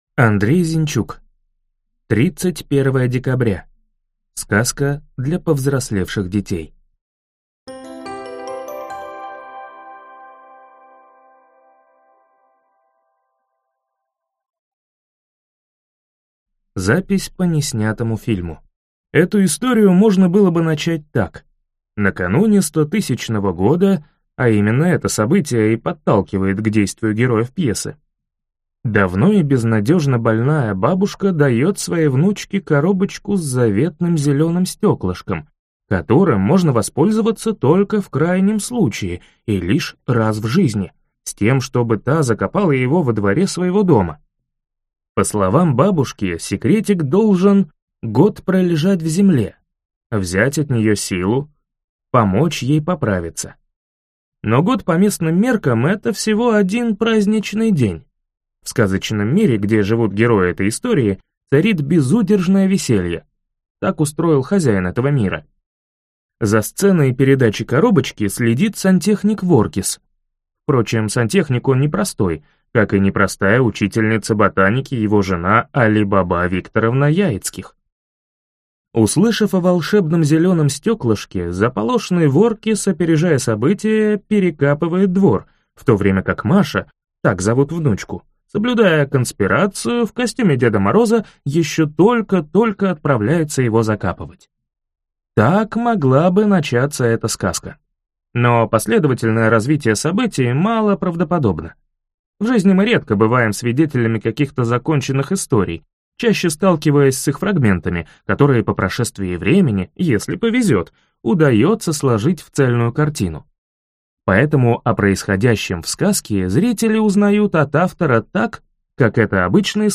Аудиокнига 31 декабря | Библиотека аудиокниг